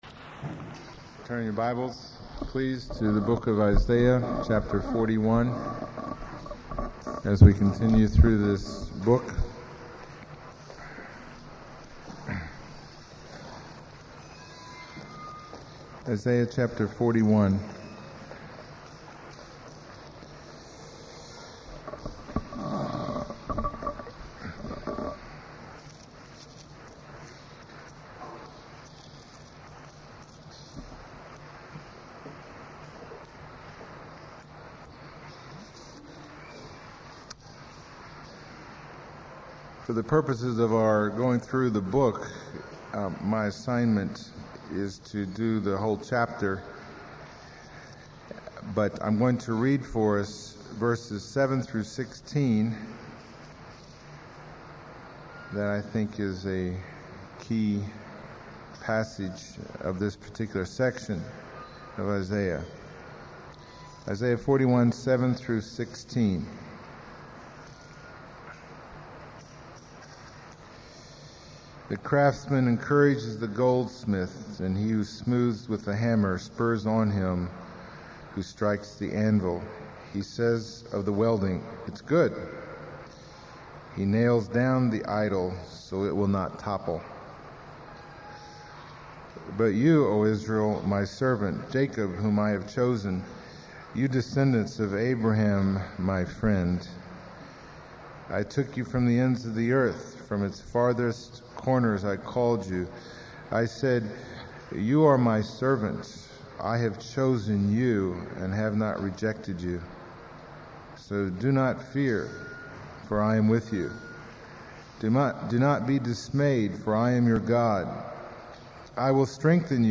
Passage: Isaiah 41:1-29 Service Type: Sunday Morning